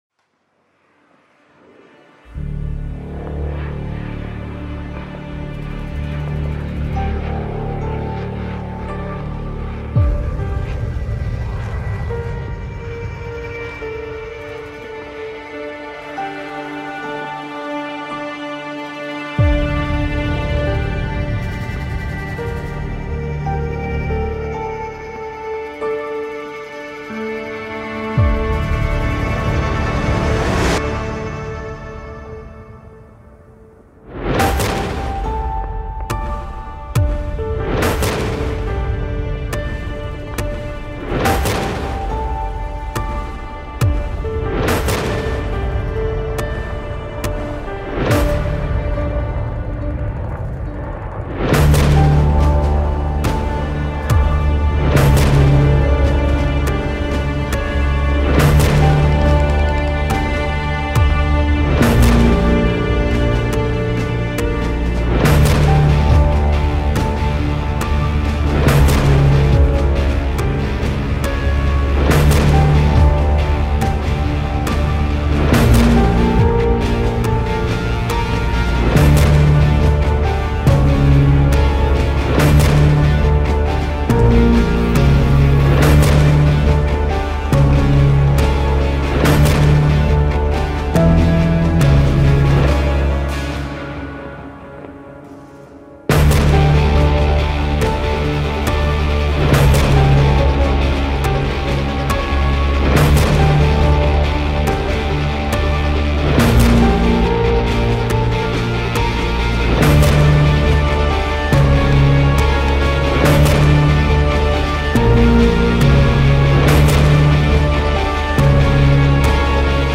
Action Trailer Background Music